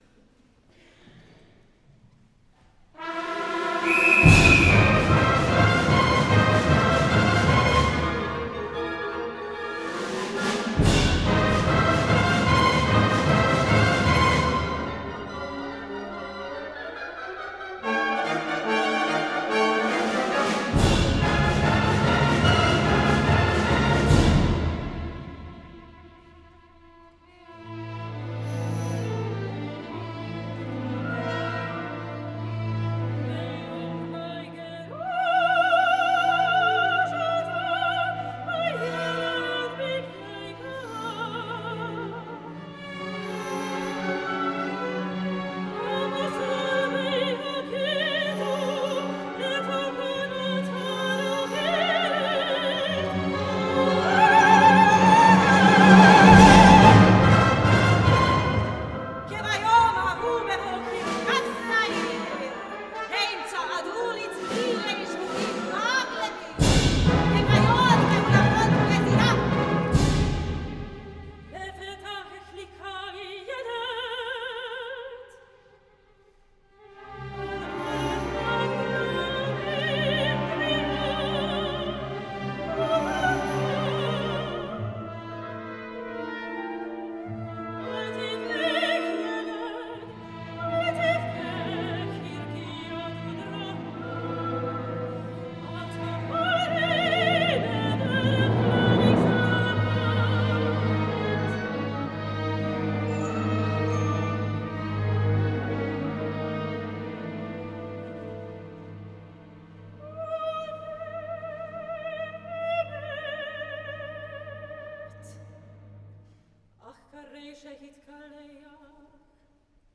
a song cycle for soprano solo and Symphony Orchestra.